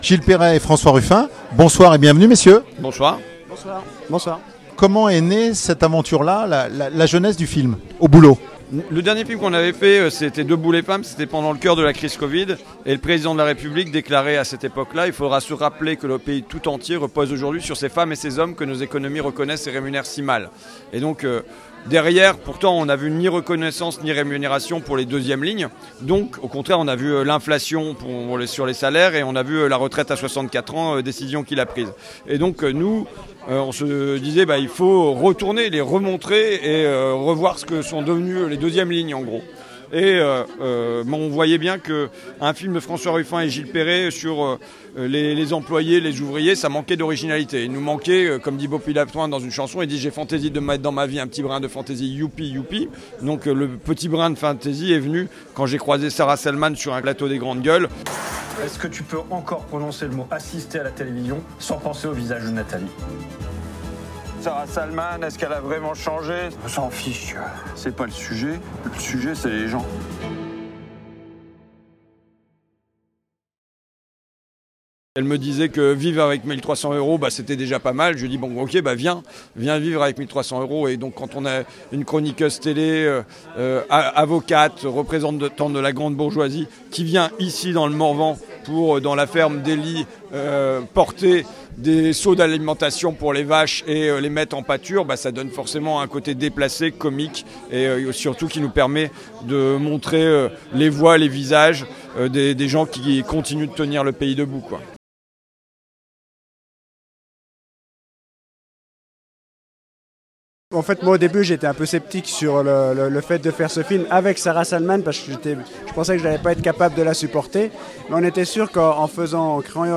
Interview réalisée à l’occasion de l'avant première au cinéma l’étoile de Château-Chinon le 25 octobre 2024.